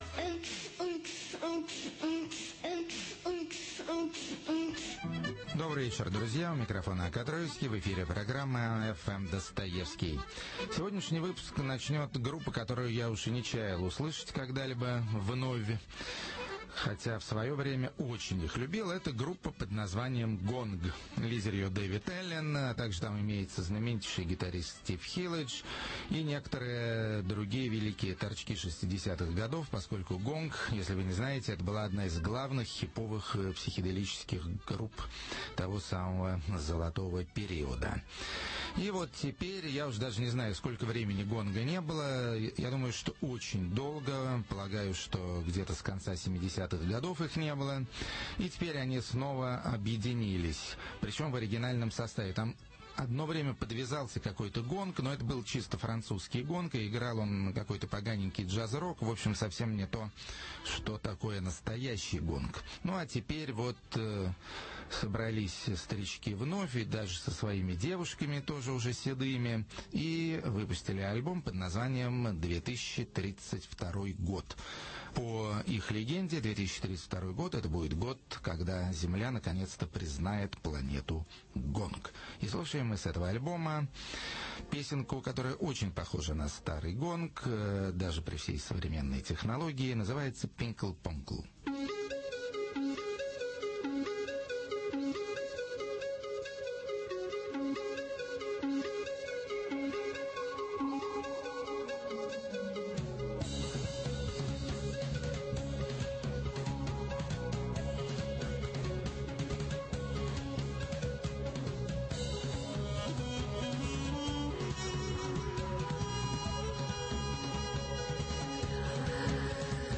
Качество ниже обычного, потому что записано из другого источника.